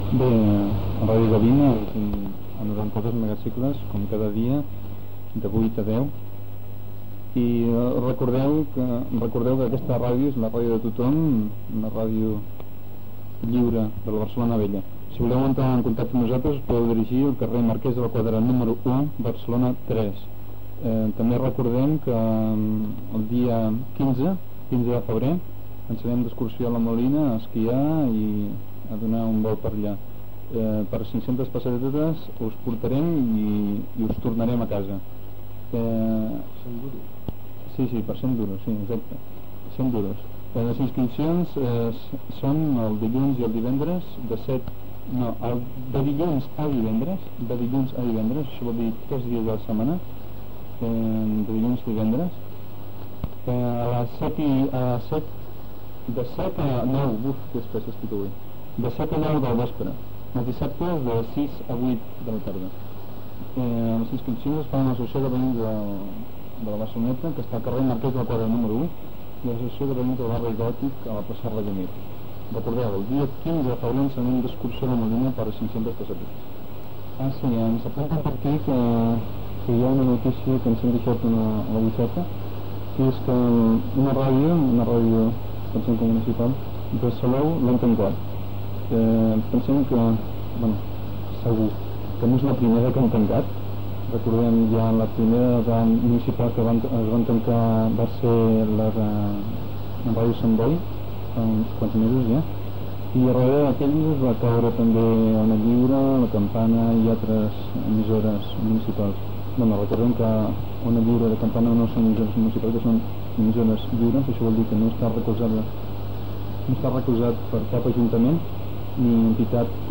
FM
Ràdio lliure barcelonesa de Ciutat Vella i la Barceloneta.